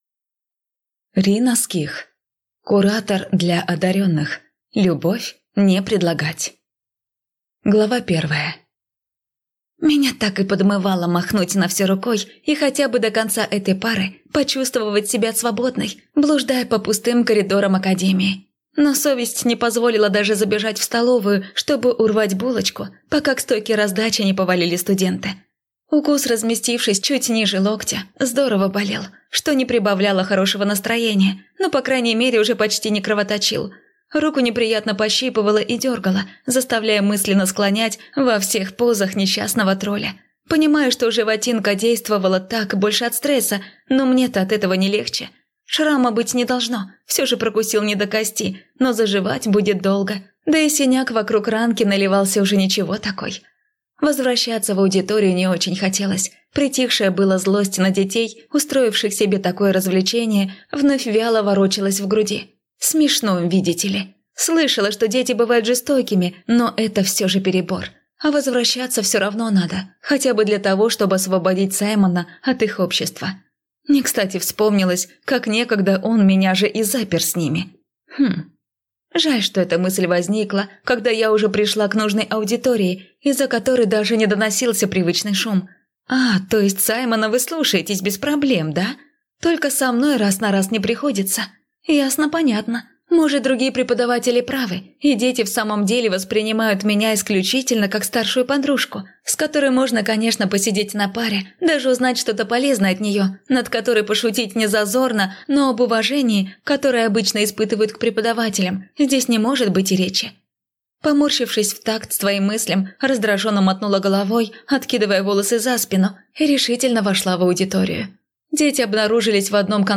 Аудиокнига Куратор для одаренных. Любовь не предлагать!